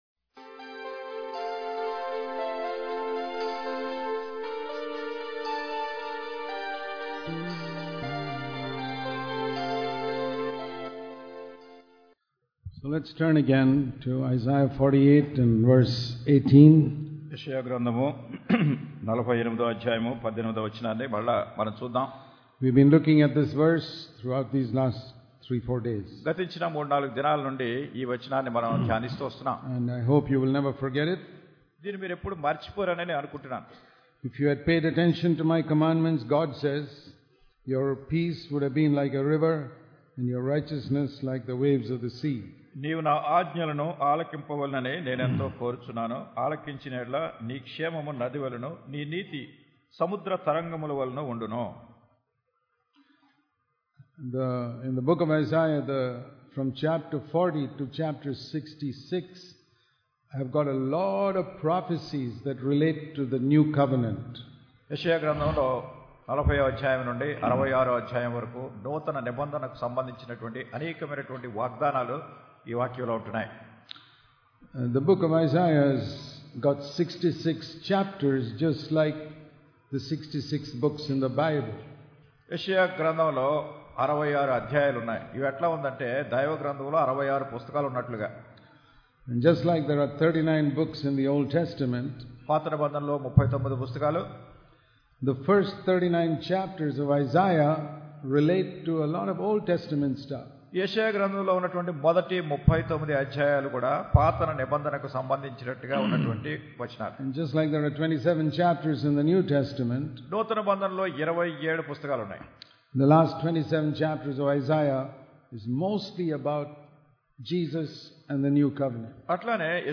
దేవుడు సాతానును మనకాళ్ళక్రింద శీఘ్రముగా చితకత్రొక్కించును కాకినాడ కూటములు 2014 ప్రసంగీకులు